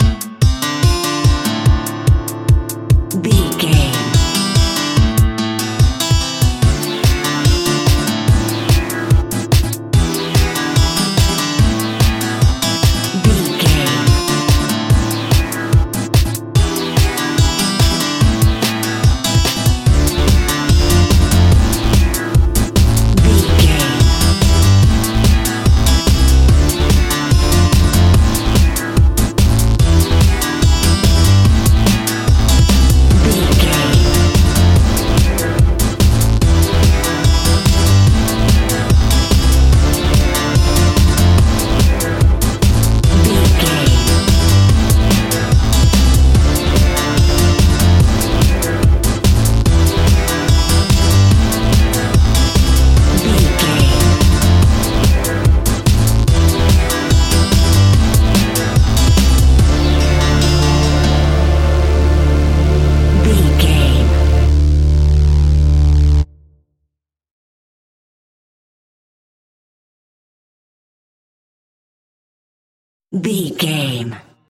Aeolian/Minor
Fast
energetic
hypnotic
industrial
drum machine
acoustic guitar
synthesiser
acid house
uptempo
synth leads
synth bass